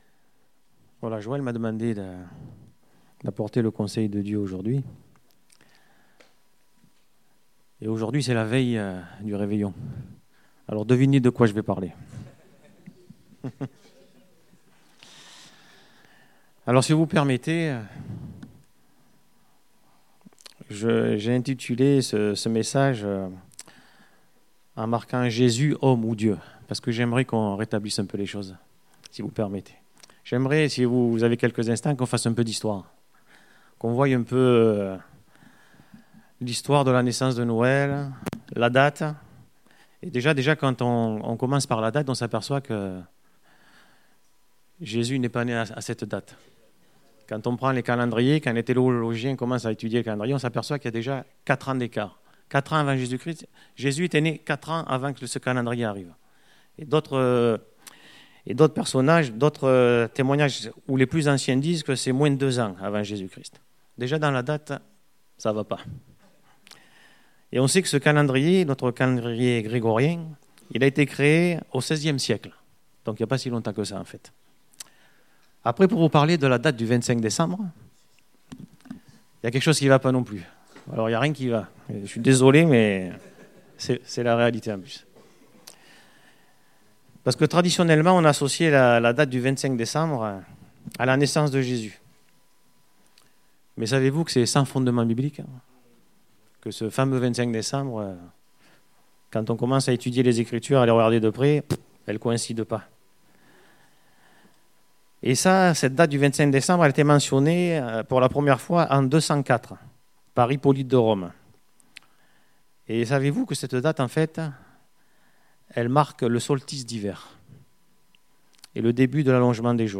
Date : 24 décembre 2017 (Culte Dominical)